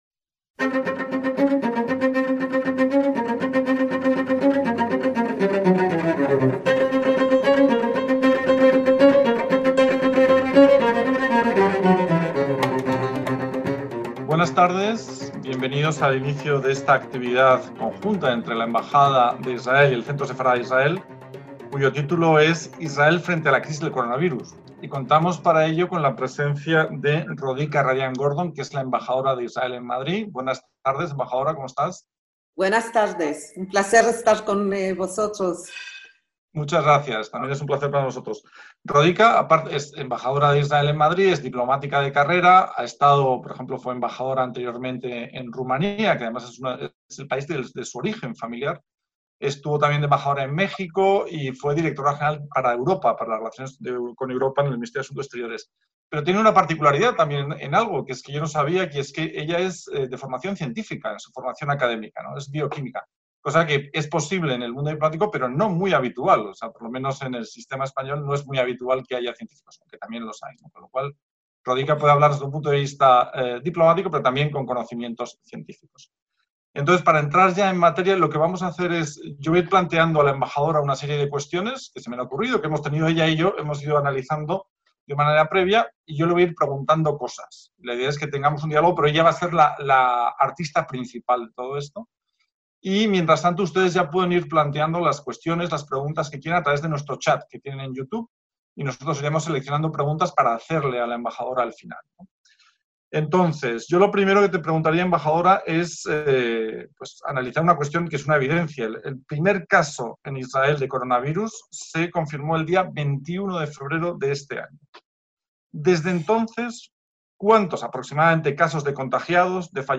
Desde ese momento el país ha puesto en marcha diferentes medidas para paliar la expansión de la pandemia. Algunas de ellas han sido el confinamiento, la concienciación social y el uso de las nuevas tecnologías. Para explicar la respuesta del país ante un virus que está afectando a la mayor parte del planeta, la Embajada de Israel y el Centro Sefarad-Israel organizaron esta conferencia online a cargo de Rodica Radian-Gordon, embajadora de Israel en España.